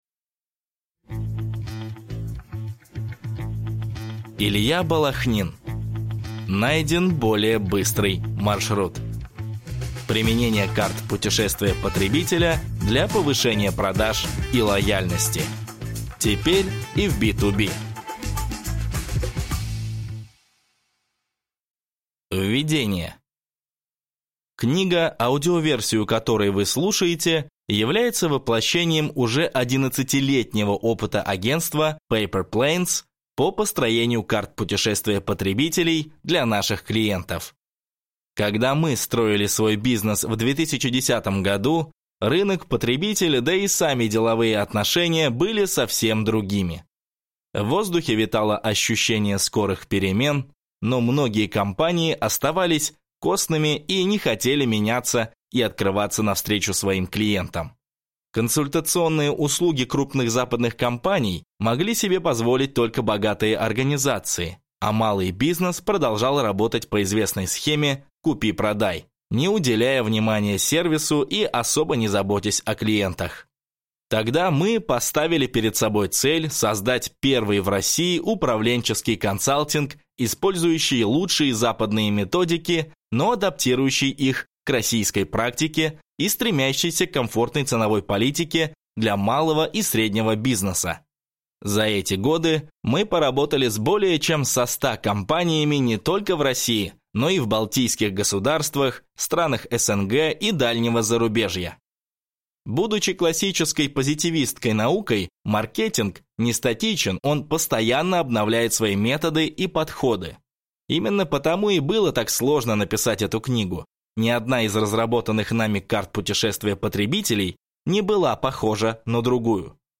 Аудиокнига Найден более быстрый маршрут. Применение карт путешествия потребителя для повышения продаж и лояльности. Теперь и в B2B | Библиотека аудиокниг